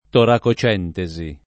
vai all'elenco alfabetico delle voci ingrandisci il carattere 100% rimpicciolisci il carattere stampa invia tramite posta elettronica codividi su Facebook toracocentesi [ torako © ent $@ i ; alla greca torako ©$ nte @ i ] → toracentesi